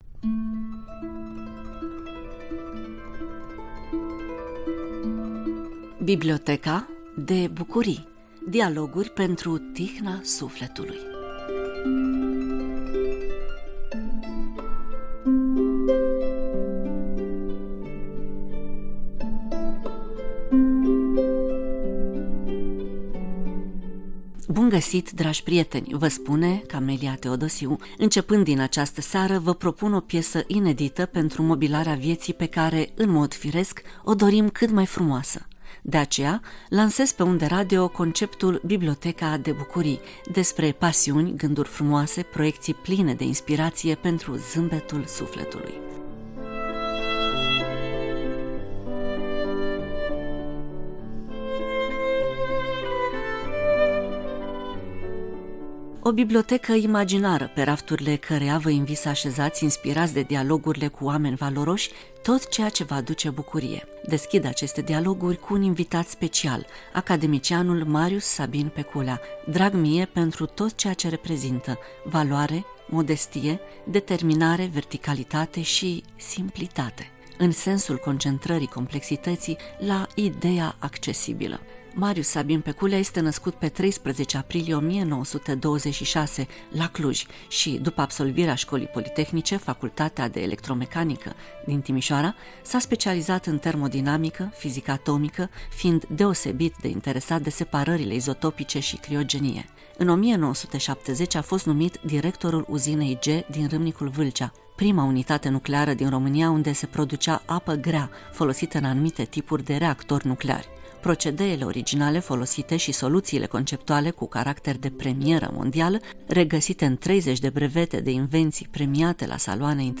Biblioteca de Bucurii – Dialoguri pentru tihna sufletului
De aceea, lansez pe unde radio conceptul: Biblioteca de bucurii – despre pasiuni, gânduri frumoase, proiecţii pline de inspiraţie pentru zâmbetul sufletului.